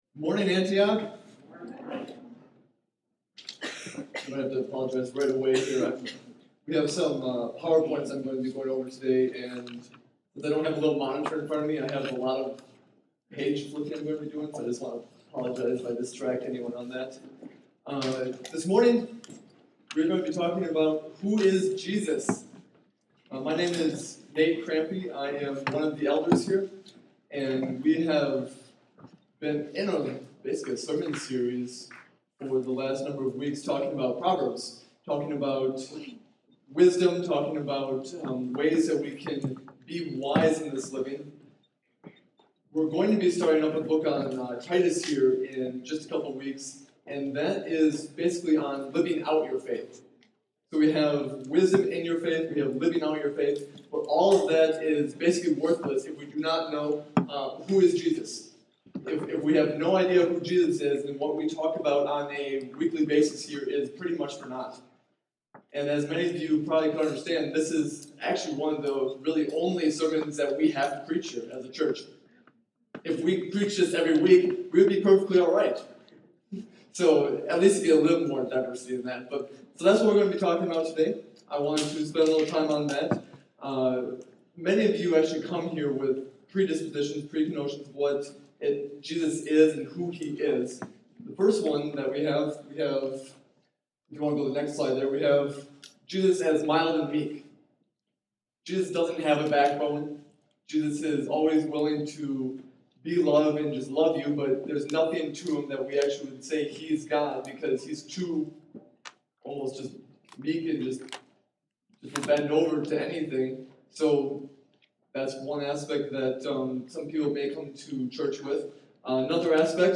Elder, preaches on "Who is Jesus?"